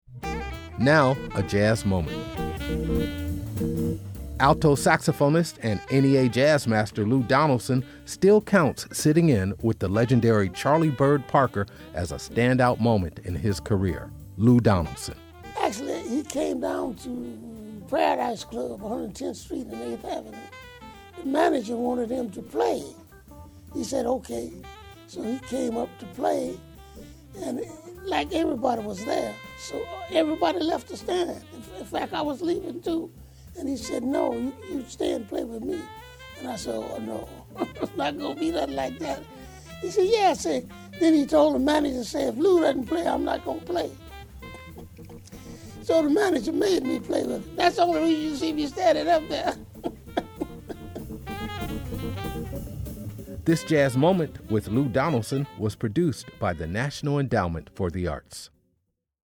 Excerpt of "Cool Blues” composed by Charlie Parker and performed by Lou Donaldson on the album, Here ‘Tis, used courtesy of Blue Note / EMI Capitol and by permission of Songs of Universal (BMI).